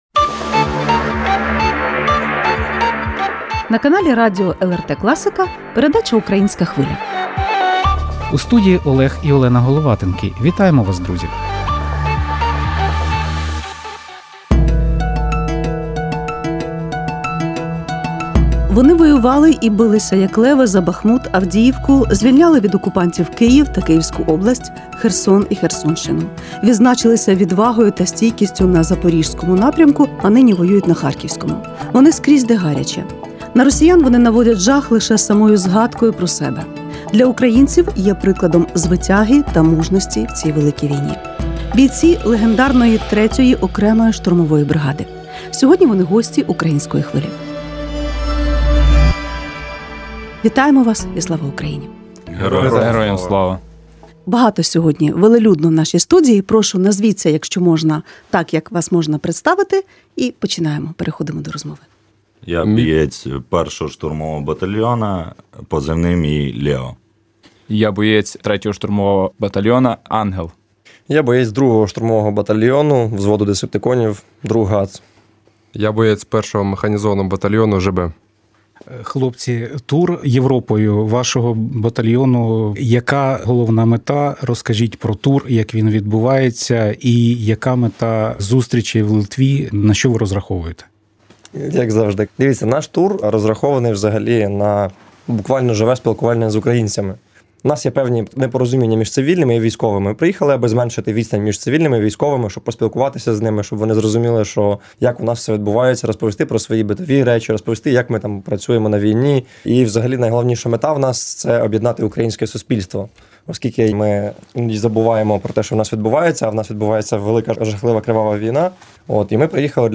Бійці 3-ї штурмової бригади у Вільнюсі. Ексклюзивне інтерв’ю
Напередодні зустрічі у Вільнюсі легендарні штурмовики завітали в студію “Української Хвилі”.